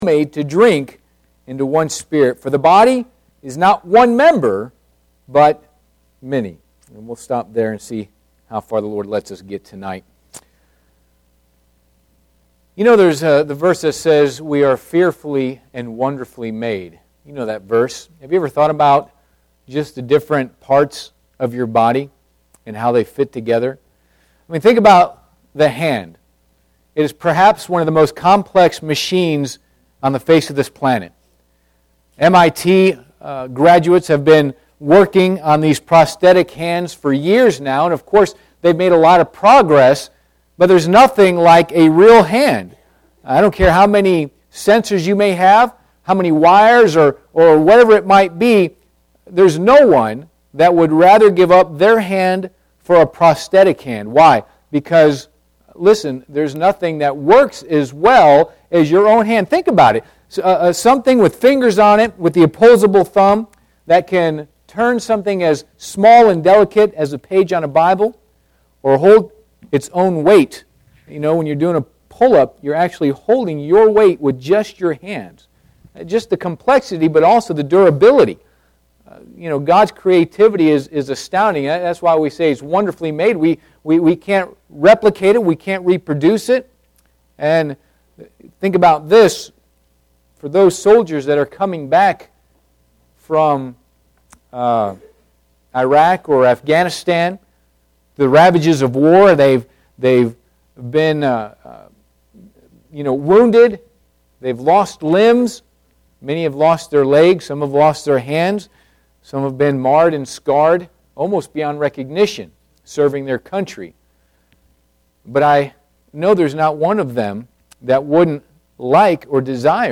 Passage: 1 Corinthians 12 Service Type: Sunday PM Bible Text